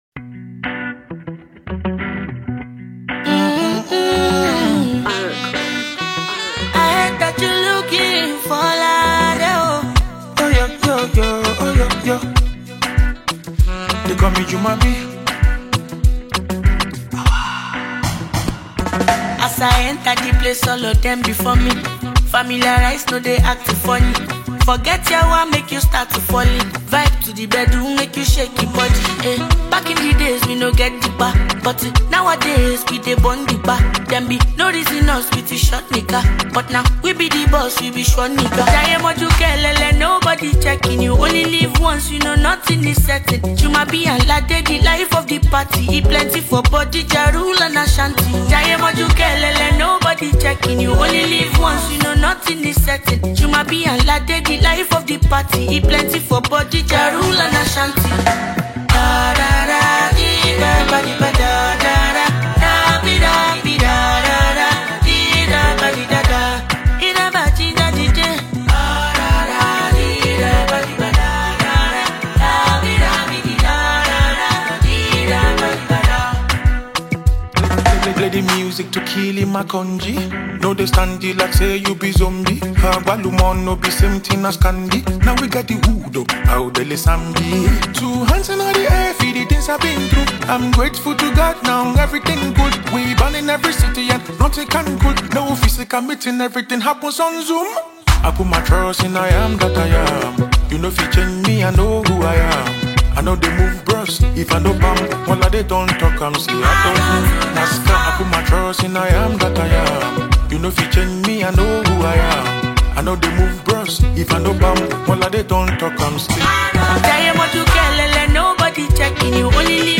is a gifted Nigerian singer